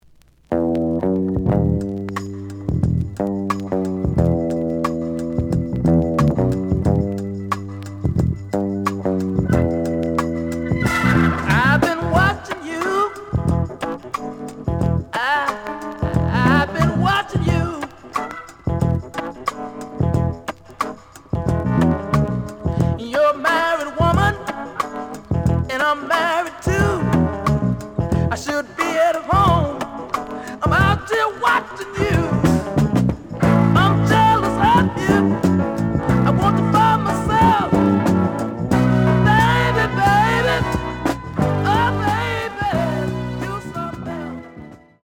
試聴は実際のレコードから録音しています。
The audio sample is recorded from the actual item.
●Genre: Funk, 70's Funk